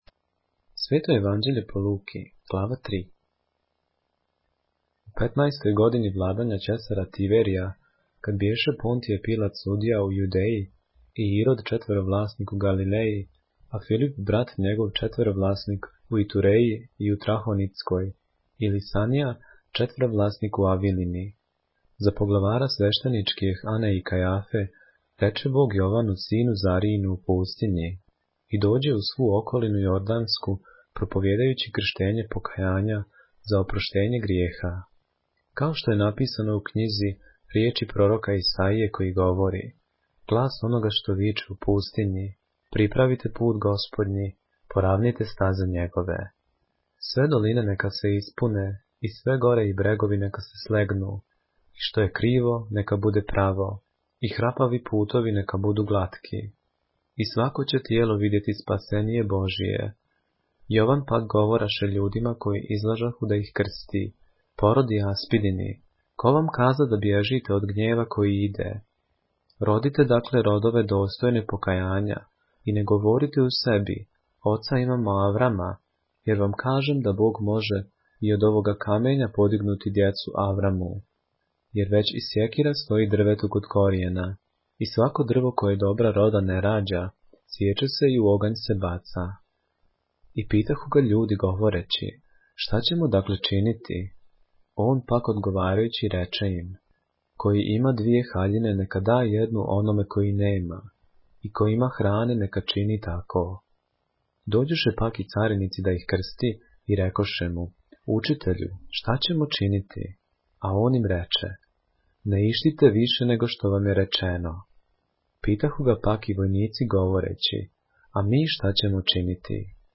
поглавље српске Библије - са аудио нарације - Luke, chapter 3 of the Holy Bible in the Serbian language